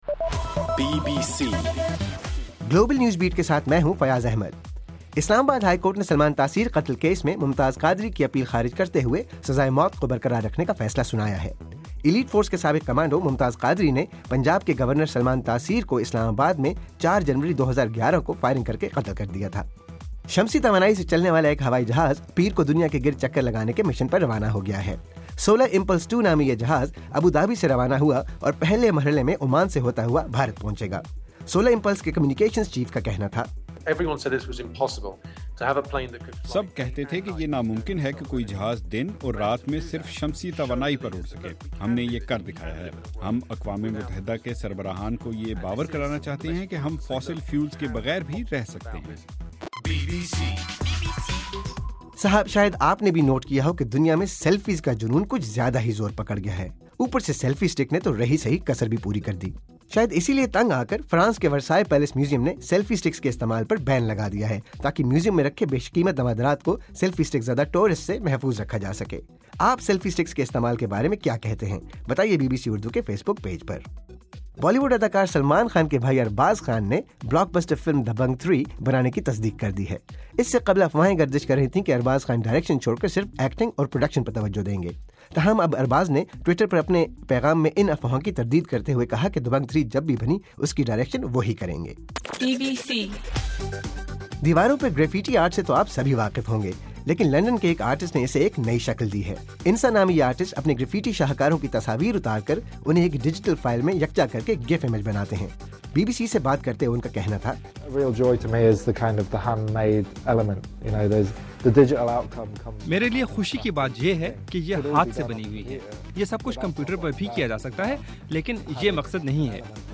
مارچ 09: رات 8 بجے کا گلوبل نیوز بیٹ بُلیٹن